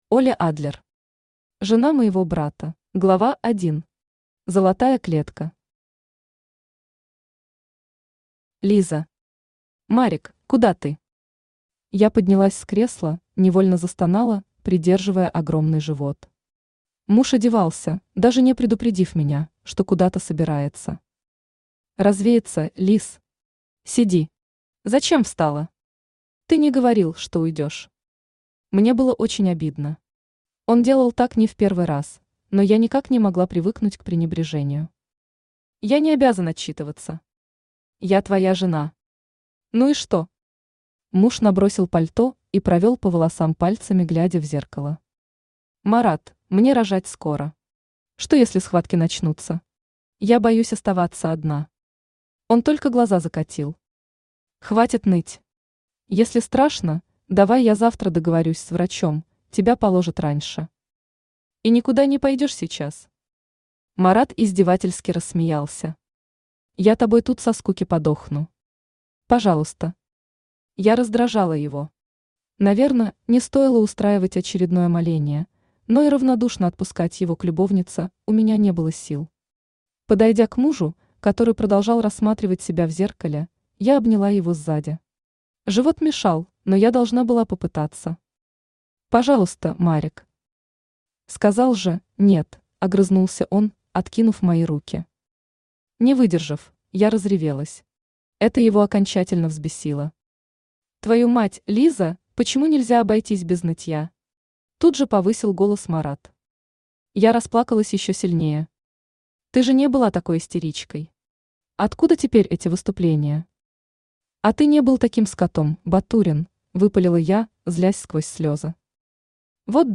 Aудиокнига Жена моего брата Автор Оле Адлер Читает аудиокнигу Авточтец ЛитРес.